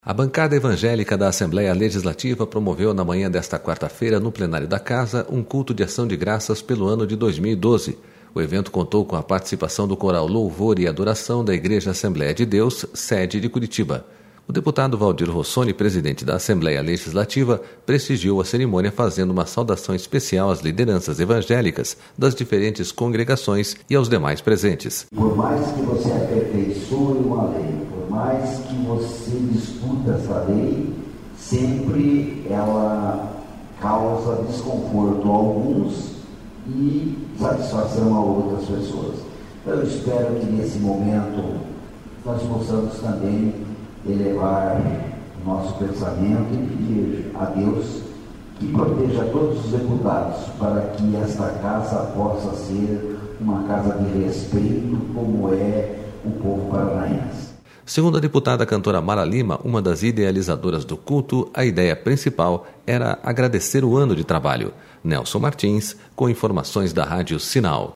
Bancada Evangélica realiza Culto de Ação de Graças na Assembleia Legislativa
A Bancada Evangélica da Assembleia Legislativa promoveu na manhã desta quarta-feira, no Plenário da Casa, um Culto de Ação de Graças pelo ano de 2012.//O evento contou com a participação do coral Louvor e Adoração, da Igreja Assembleia de Deus, sede de Curitiba.O deputado Valdir Rossoni, presidente...